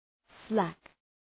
Προφορά
{slæk}